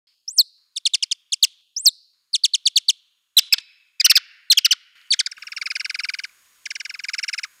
The Love Songs of Free-Tailed Bats
Note: recording slowed by a factor of 8
She concluded that free-tailed bat songs are composed of highly stereotyped phrases hierarchically organized by a common set of syntactical rules.
free-tail_bat.wav